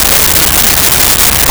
Car Engine Run Loop 01
Car Engine Run Loop 01.wav